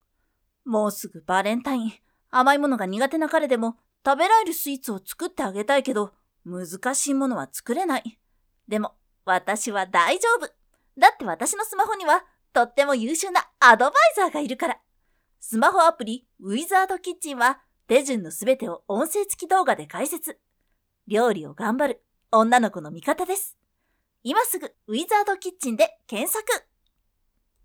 V O I C E
アプリCM風